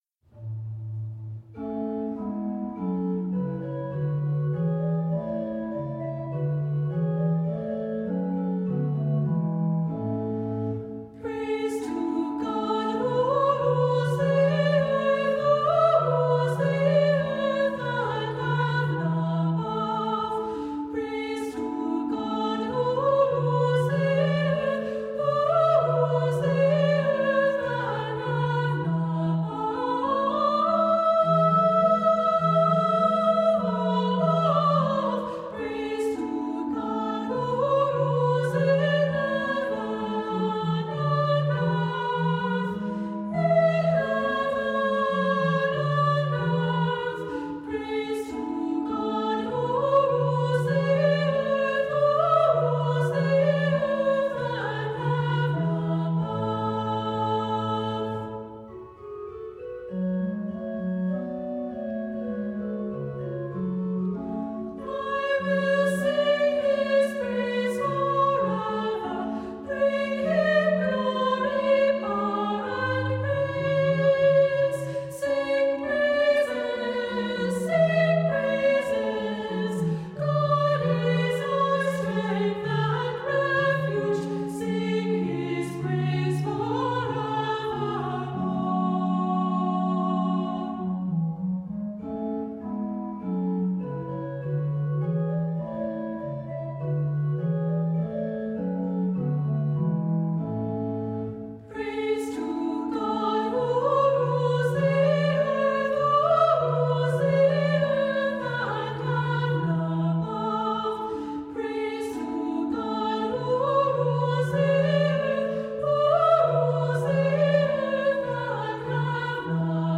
Accompaniment:      With Piano
Music Category:      Christian